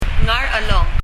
Ngarchelong　　　[ŋar ! ɛlon]
発音
もうすこしネイティブの発音に近づけて表記するなら、「ガルァロン」、でしょうか。